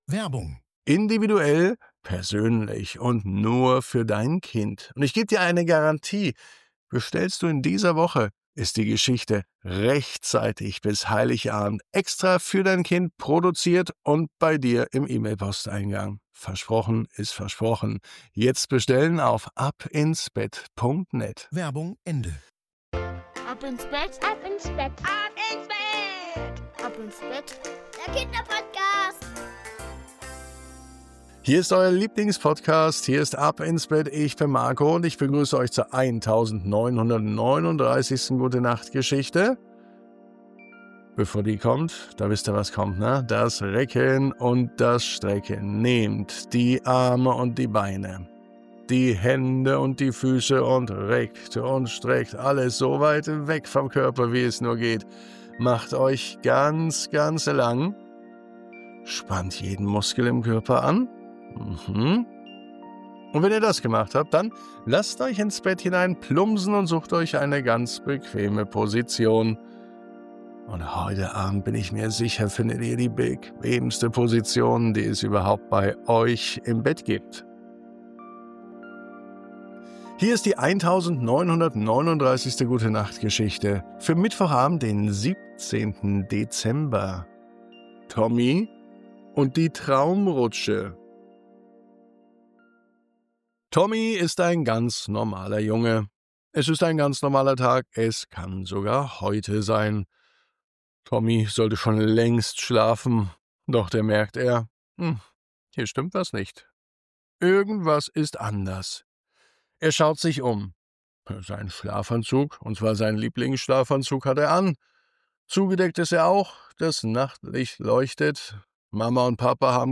Eine gemütliche Ab-ins-Bett-Geschichte zum Einschlafen und Wohlfühlen.